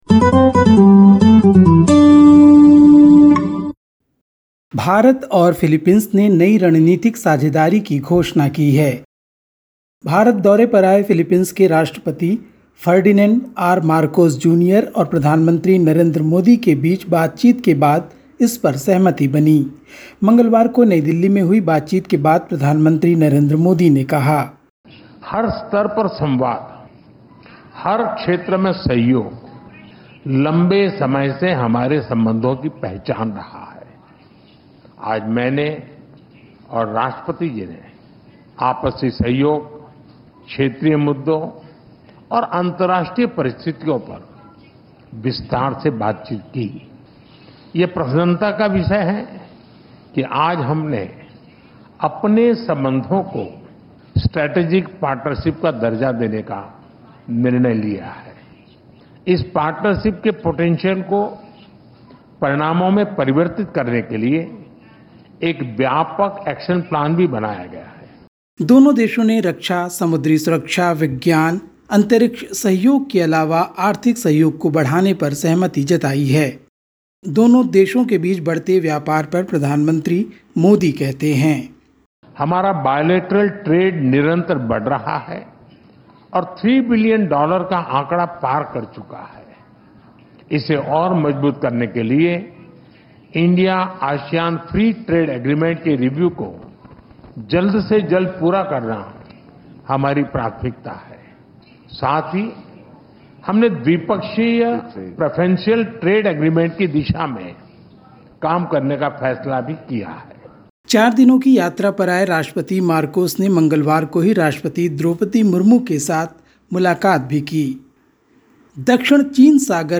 Listen to the latest SBS Hindi news from India. 6/08/25